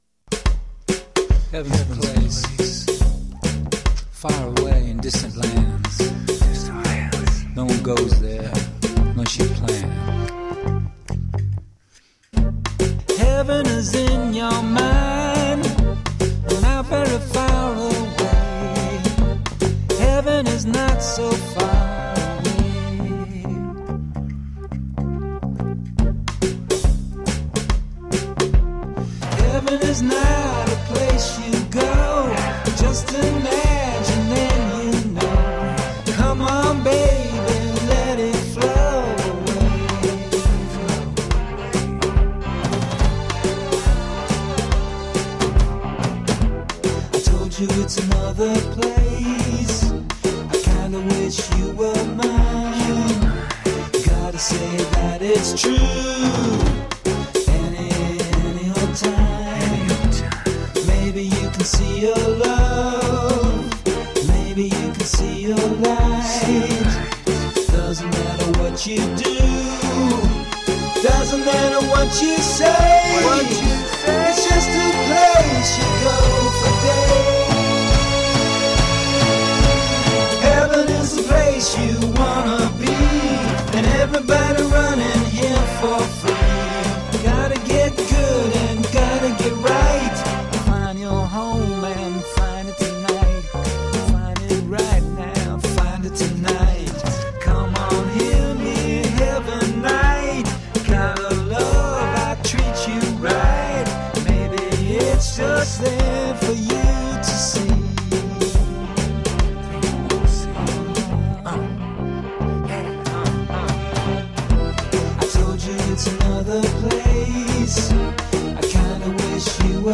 Rock in the style of the 1960's british wave.
Tagged as: Alt Rock, Other, Prog Rock